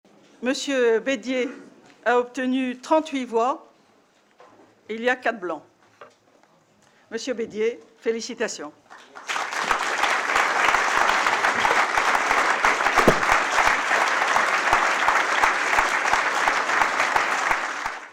2 avril 2015 Bédier présidentPierre Bédier a été élu Président du Conseil départemental des Yvelines, ce jeudi 2 avril à l’Hôtel du Département.
Christine Boutin annonce le résultat du « 3e tour » :
Proclamation-des-resultats.mp3